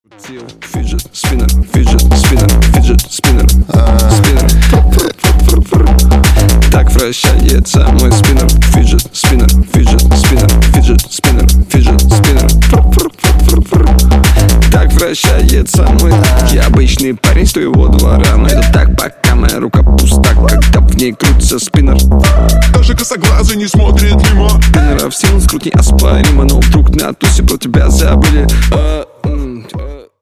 • Качество: 128, Stereo
Хип-хоп
русский рэп
club
качающие